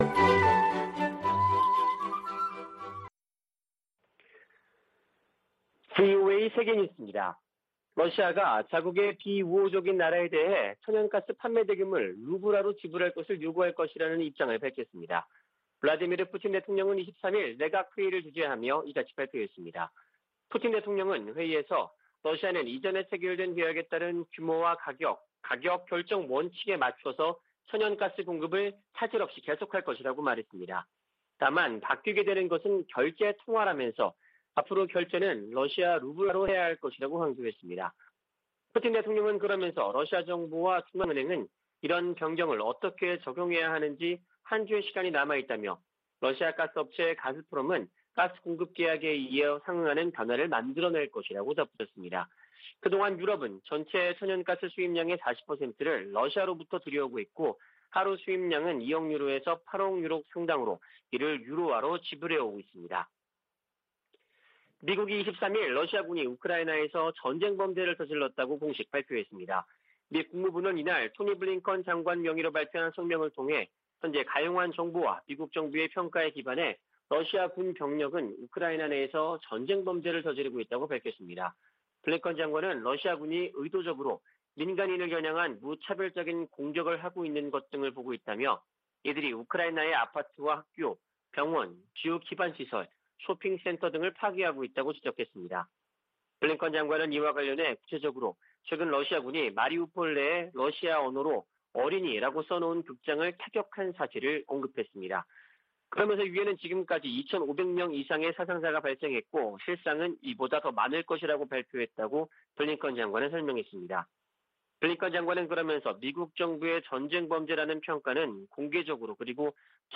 VOA 한국어 아침 뉴스 프로그램 '워싱턴 뉴스 광장' 2022년 3월 24일 방송입니다. 북한 해킹 조직이 러시아 등 사이버 범죄자들과 협력하고 있다고 백악관 국가안보보좌관이 지적했습니다. 독자 대북제재를 강화하고 있는 조 바이든 미국 행정부는 지난 3개월간 20건이 넘는 제재를 가했습니다.